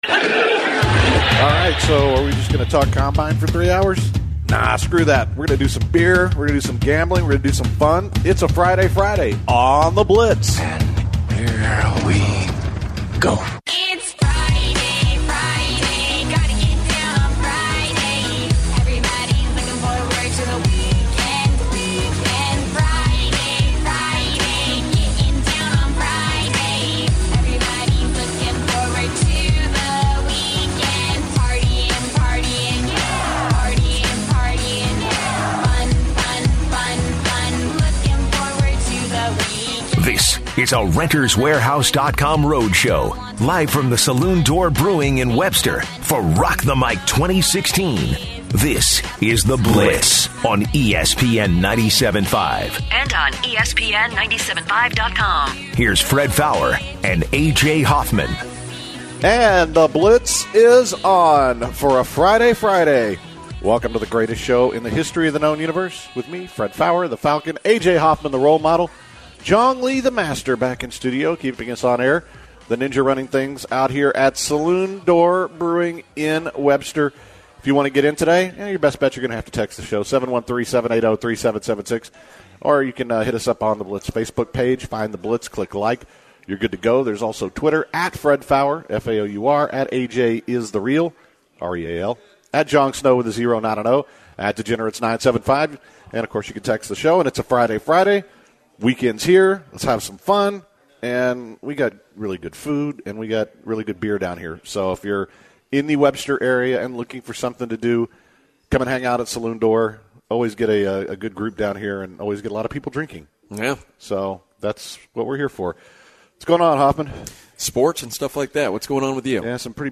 It’s a Friday on The Blitz and the guys are out at Saloon Door Brewery. In the first hour of the show the guys talked mostly NFL combine. They also had a lengthy discussion about recent MMA events.